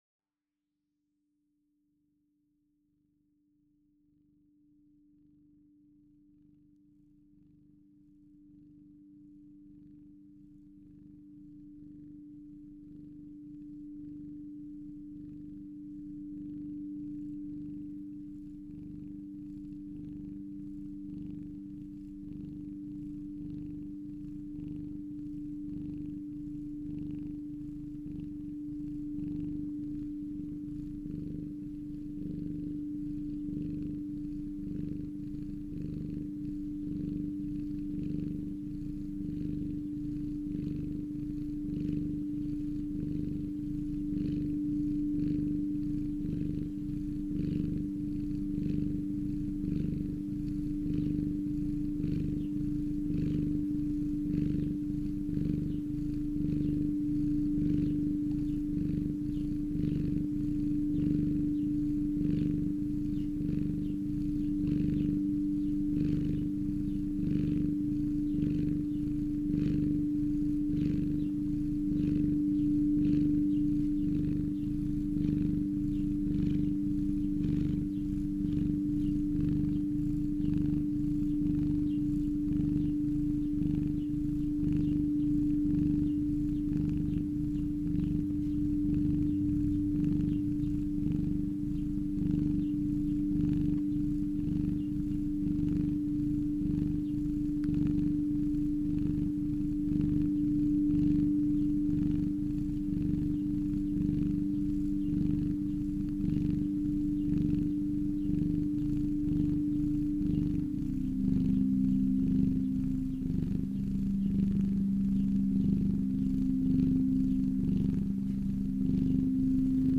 Plus, get a free soundbath featuring real cat purr frequencies.